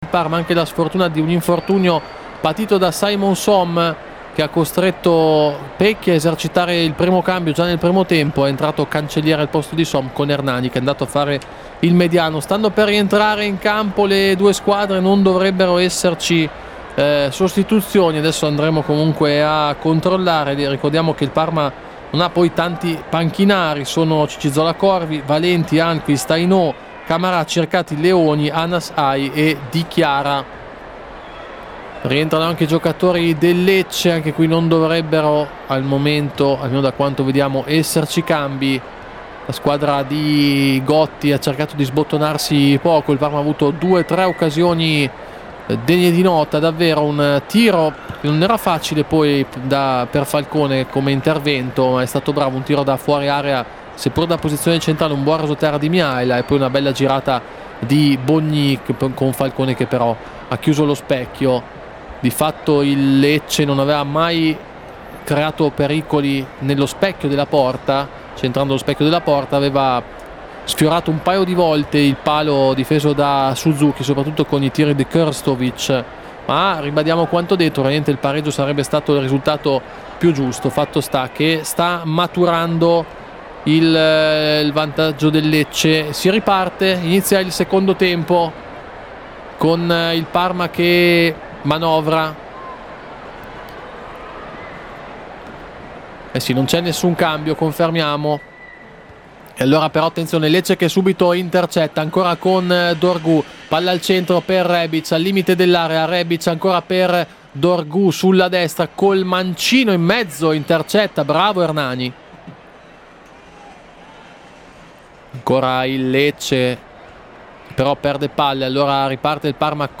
Radiocronaca